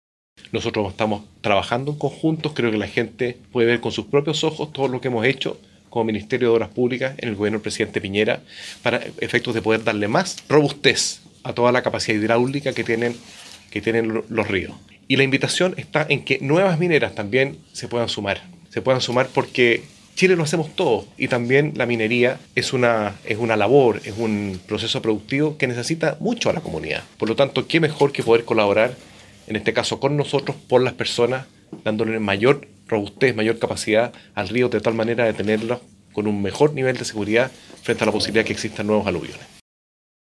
La autoridad nacional agradeció el trabajo y la preocupación del alcalde Mario Morales y de la empresa Minera Candelaria, por el interés presentado en la comunidad y su bienestar: